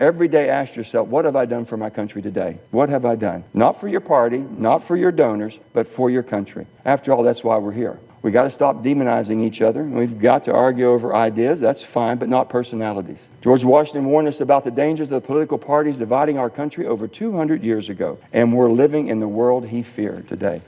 Manchin Makes Final Speech in U.S. Senate
West Virginia Senator Joe Manchin made his final speech before leaving office in the United States Senate this week in Washington, D.C.  Manchin entered the Senate as a Democrat in 2010, winning a special election following the death of Senator Robert Byrd. He looked back on his years a lawmaker, but challenged his fellow Senators to look across party lines in the future…